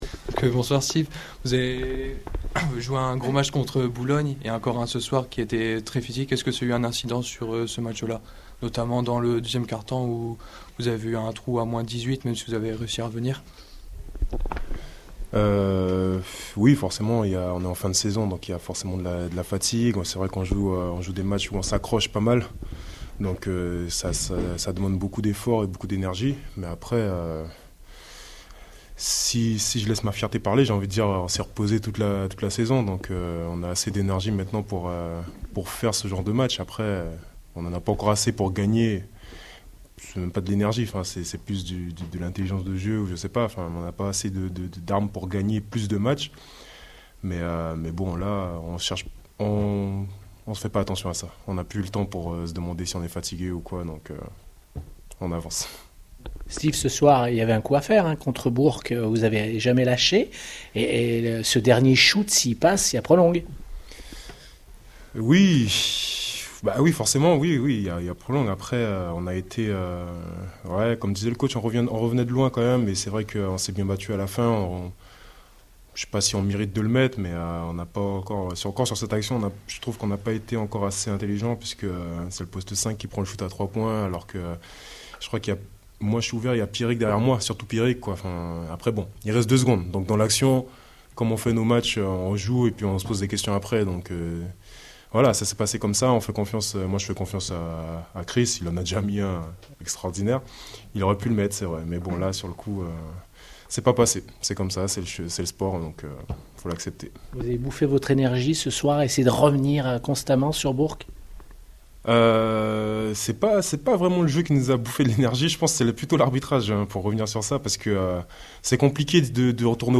Interviews d'après match - JL Bourg Basket